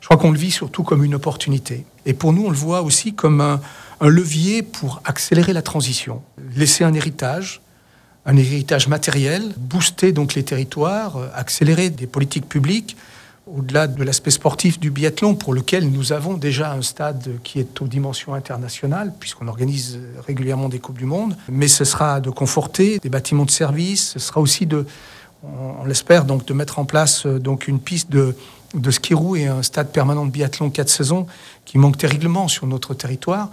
ITG André Perillat-Amédé – Ski roue Grand Bornand